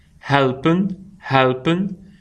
PRONONCIATION :